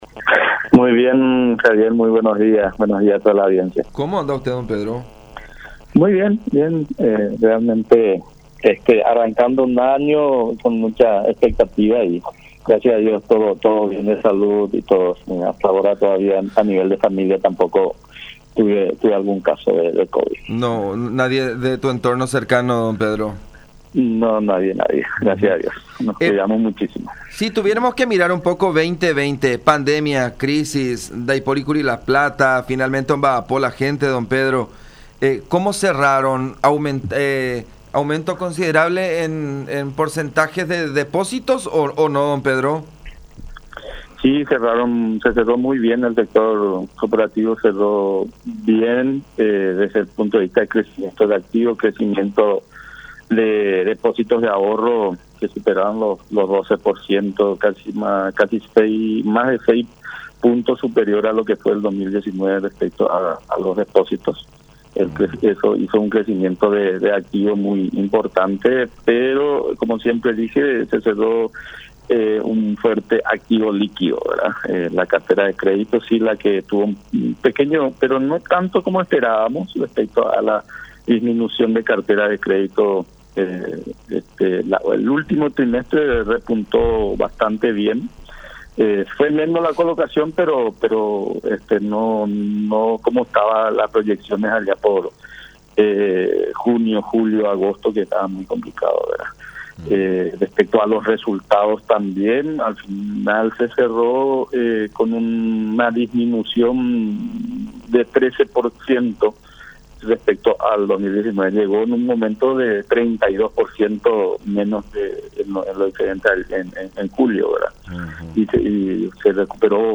“Se cerró muy bien desde el punto de vista de crecimiento de activos, en 10%, y de depósitos de ahorro, donde se superó el 12%, que es más de 6 puntos por encima al año pasado. Se culminó mucho mejor a comparación de lo que preveíamos allá por junio, julio y agosto”, destacó Pedro Loblein, presidente del INCOOP, en contacto con La Unión, en referencia a los números que dejó el 2020.